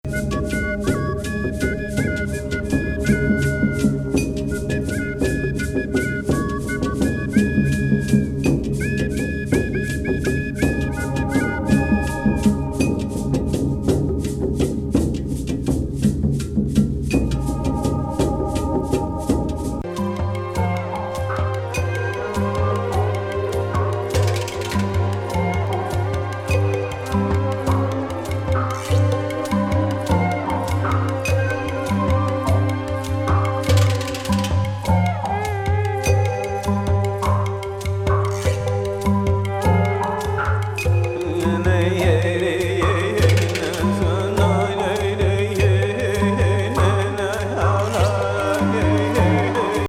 ・ディープ・アンビエンスで入り込むインナー・トリッピー・サウンドスケープ。